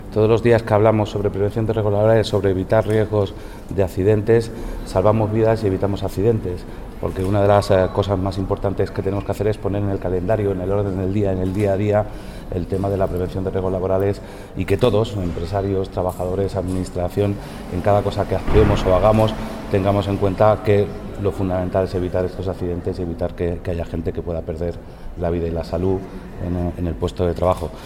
El director de Trabajo, Formación y Seguridad Laboral, Eduardo del Valle, habla de la importancia de poner en la agenda pública la prevención de riesgos laborales.